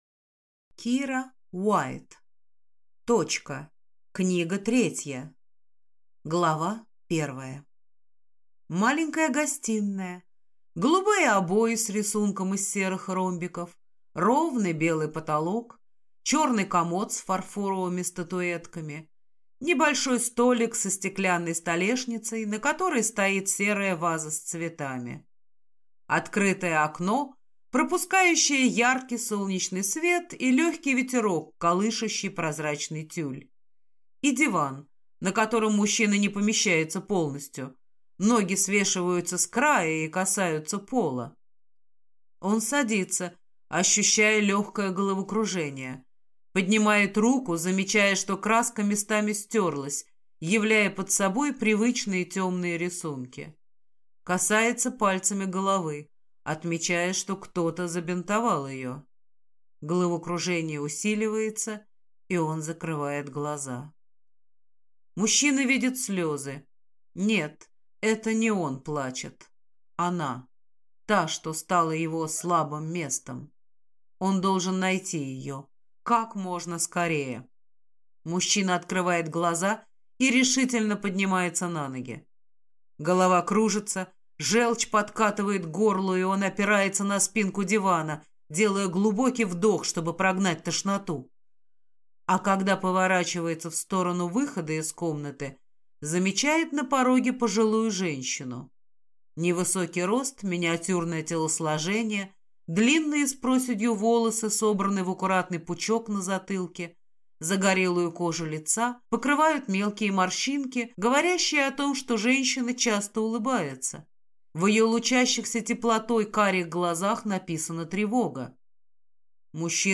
Аудиокнига Точка. Книга 3 | Библиотека аудиокниг